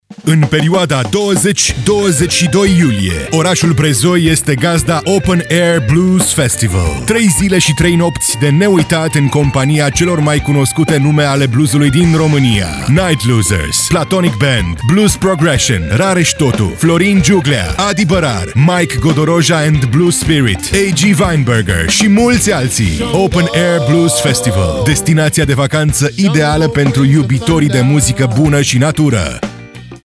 SPOT-BLUES-BREZOI-FESTIVAL.mp3